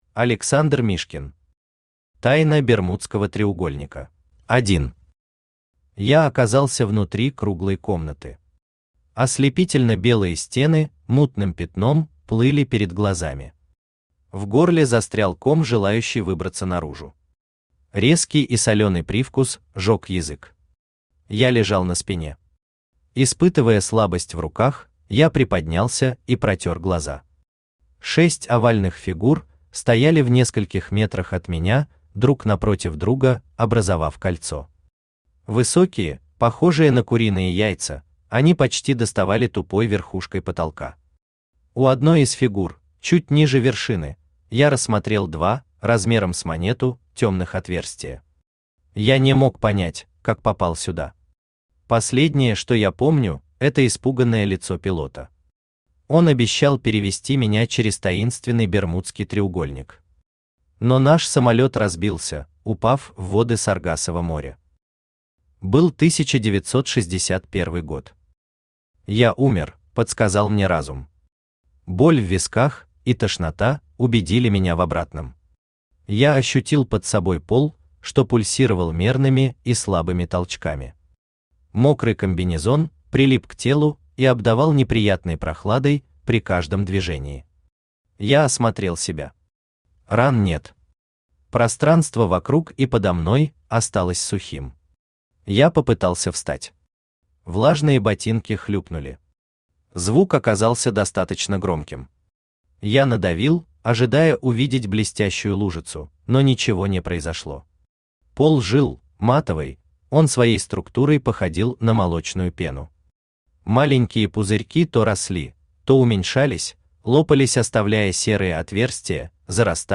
Аудиокнига Тайна Бермудского треугольника | Библиотека аудиокниг
Aудиокнига Тайна Бермудского треугольника Автор Александр Александрович Мишкин Читает аудиокнигу Авточтец ЛитРес.